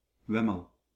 Wemmel (Dutch pronunciation: [ˈʋɛməl]
Nl-Wemmel.ogg.mp3